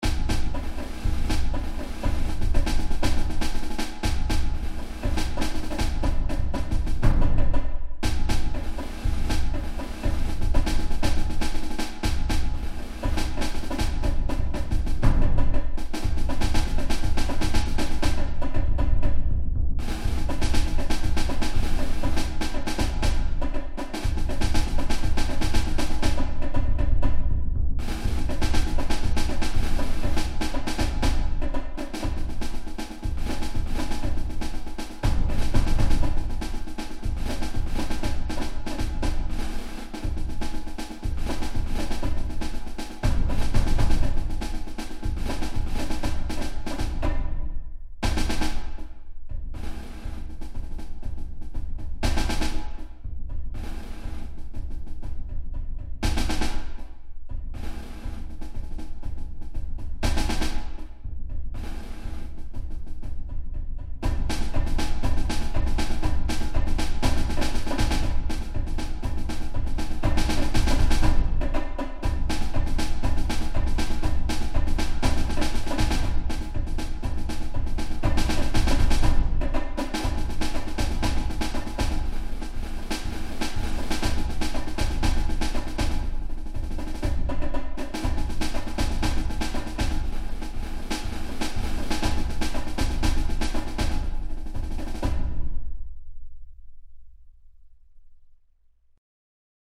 Ongestemd Marcherend Slagwerk
Snare drum Field drum Bass drum